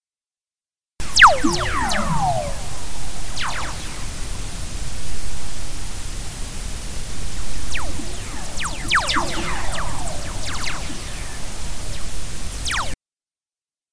These signals were recorded by the Van Allen Probes A spacecraft EMFISIS Waves instrument on New Year's Eve, December 31, 2015, at about 12:04 UTC. The measurements of three orthogonal magnetic antennas Bu, Bv, and Bw were combined to make the 12 second stereo audio recording.
These signals, called whistlers, are created by lightning discharges in Earth's atmosphere which then propagate back and forth along magnetic field lines with lower frequencies being delayed as they travel through the thin charged gas of space. The resulting spread in frequency produces a whistling tone with the dispersion related to the quantity of plasma the signal has traversed. As a bonus, there are a few distinct proton whistlers which are the low frequency rising tones just following the more common falling tone.